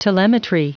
Prononciation du mot telemetry en anglais (fichier audio)
Prononciation du mot : telemetry